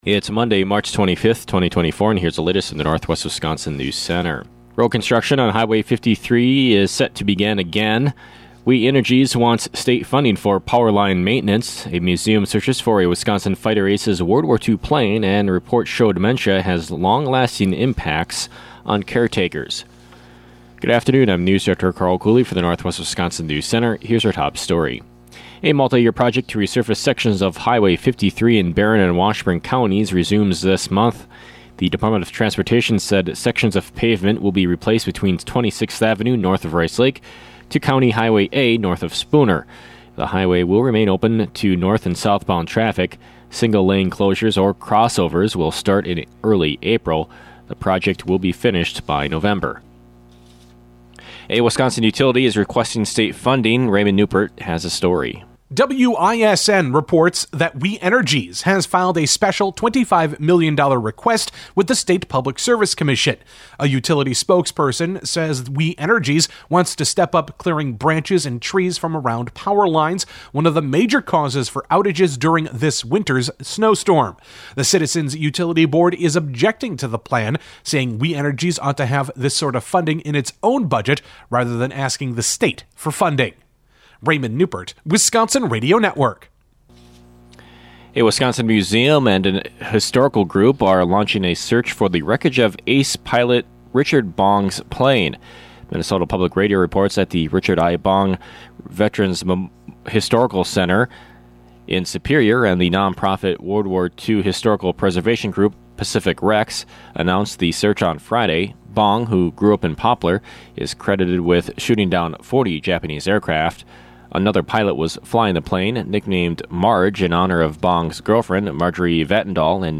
PM NEWSCAST – Monday, March 25, 2024